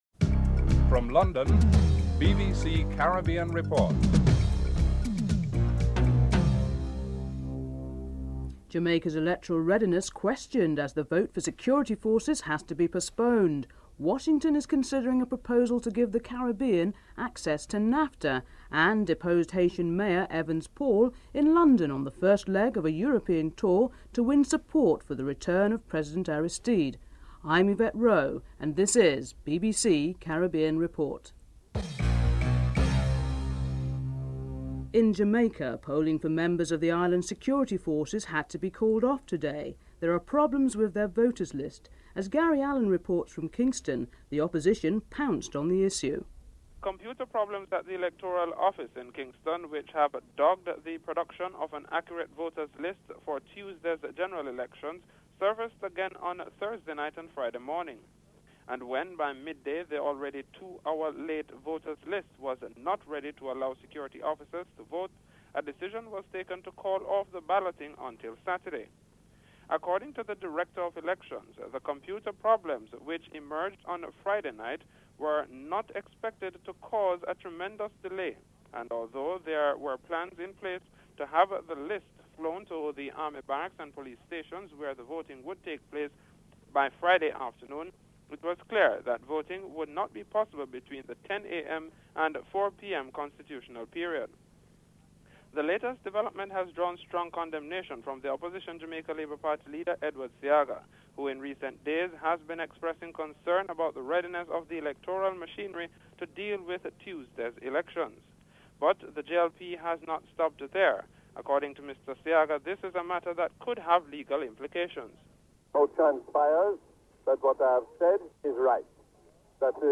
Headlines with anchor